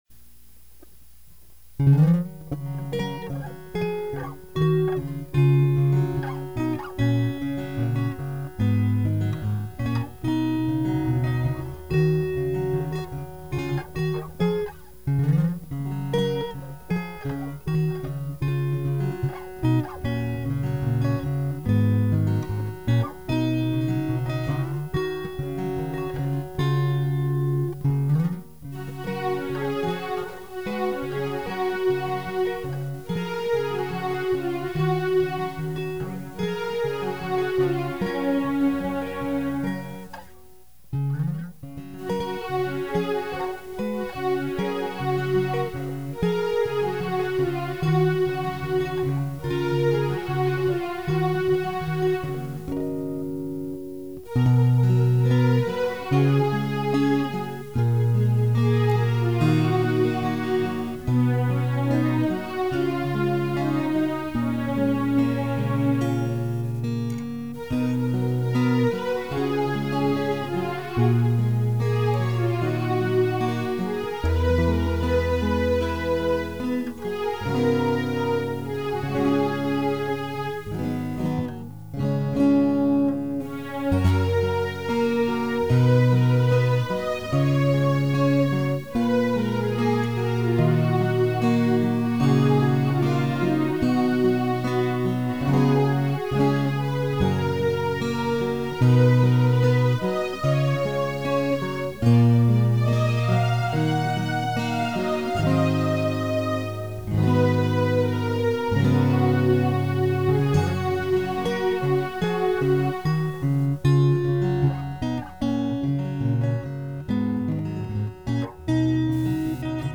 TR1 A-Giter
TR2 A-Giter
TR3 synth
イントロのギターがカッコイイので練習して弾いてみました
ギター2本でステレオにして録音してます。